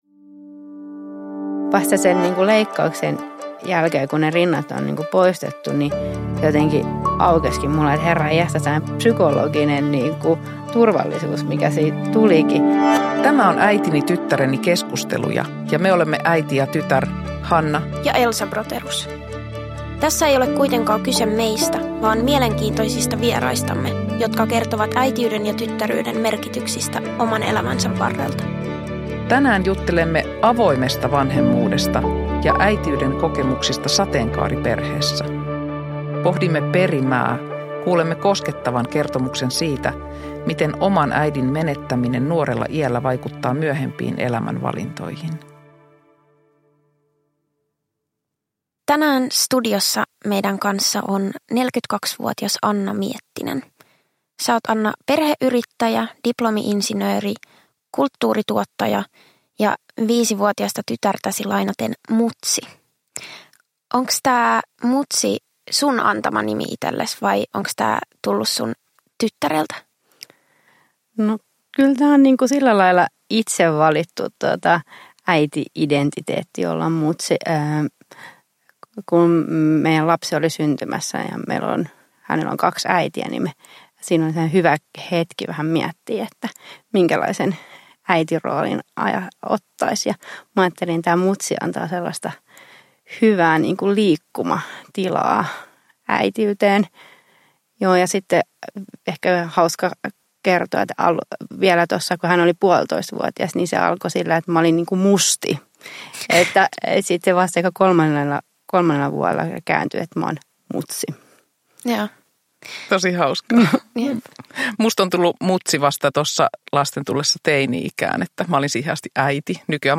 Avointa ja koskettavaa keskustelua äitinä ja tyttärenä olemisesta
• Ljudbok